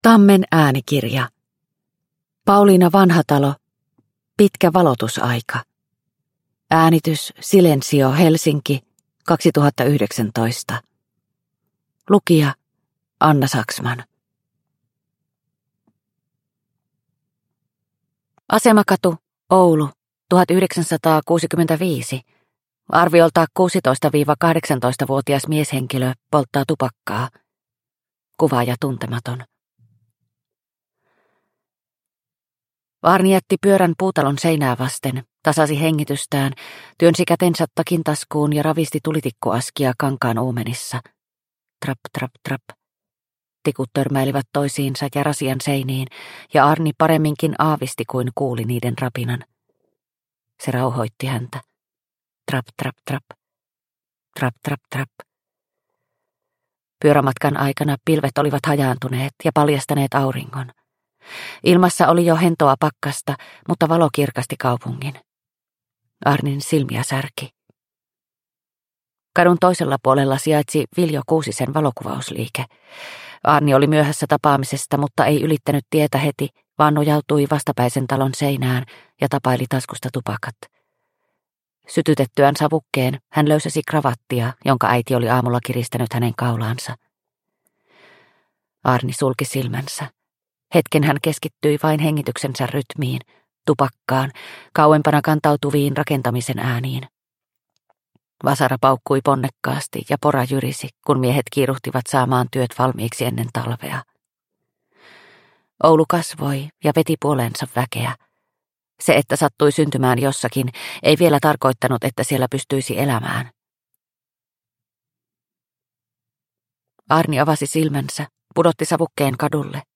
Pitkä valotusaika – Ljudbok – Laddas ner